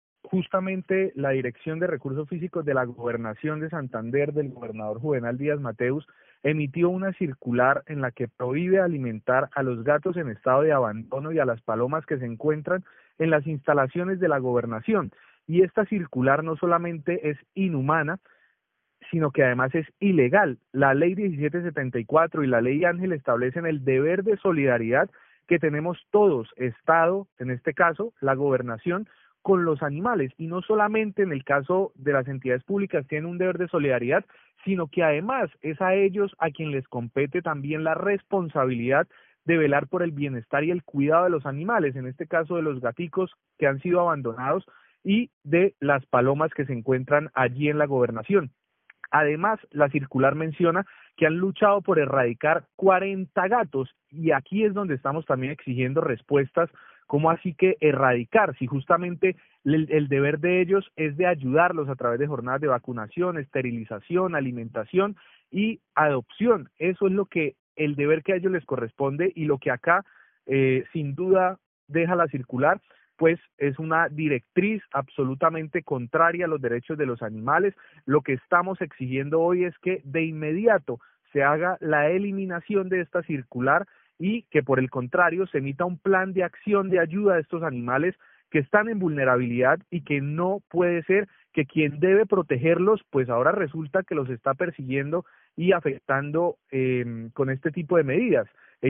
Camilo Machado, concejal de Bucaramanga